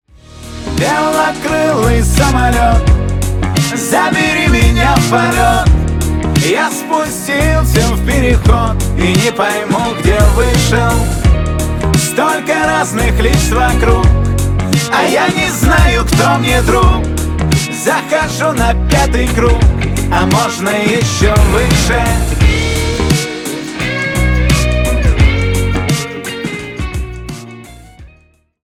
Рок Металл
спокойные